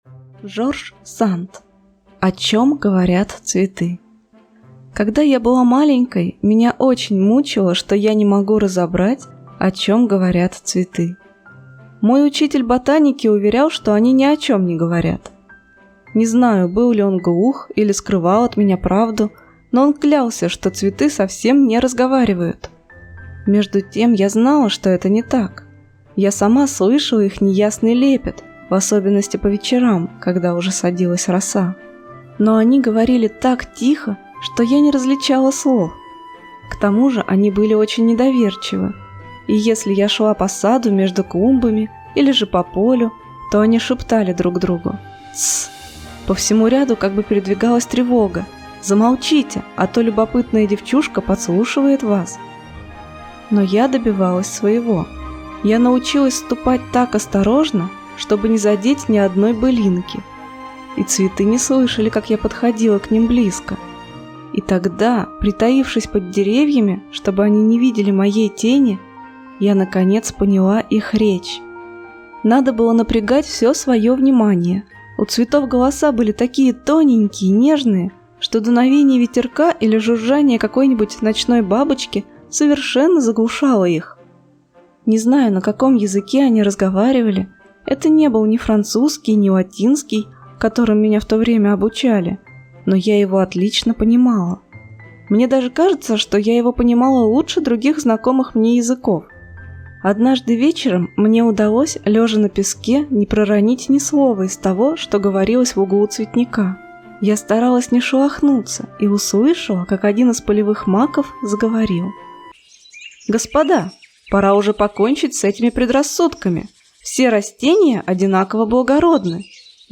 О чем говорят цветы - аудиосказка Жорж Санд - слушать онлайн